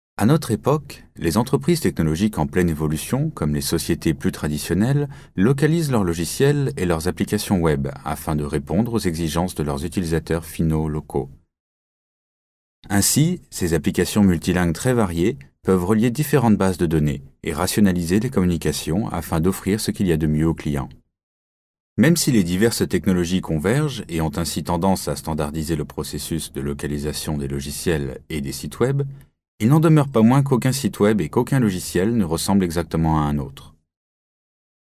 French Male 02289
NARRATION